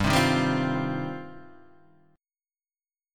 G 7th Suspended 4th Sharp 5th
G7sus4#5 chord {3 3 1 0 1 1} chord